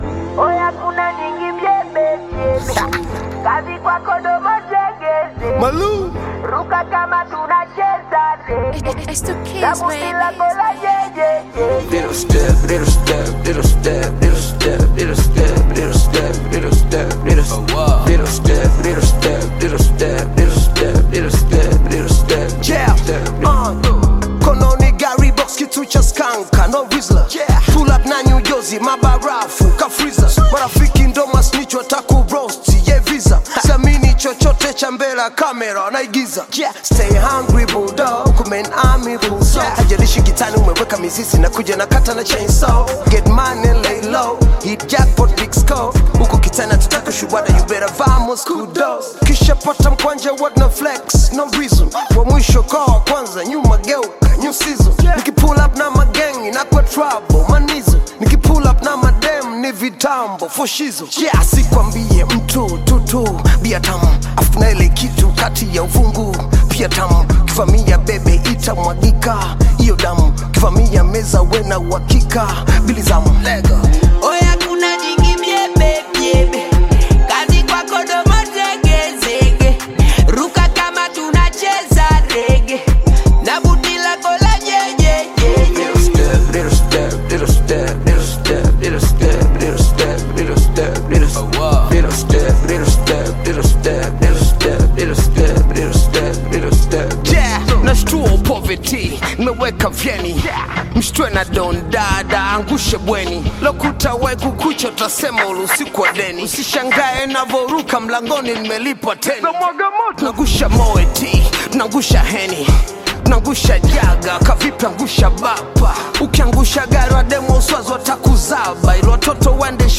Tanzanian Hip-Hop recording artist
African Music